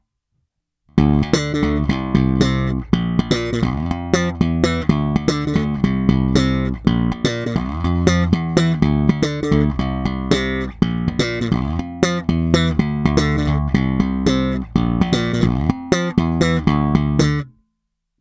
Není-li uvedeno jinak, následující nahrávky jsou provedeny rovnou do zvukovky, jen normalizovány a dále ponechány bez jakéhokoli postprocesingu.
Slap na oba snímače
Slap bez EQ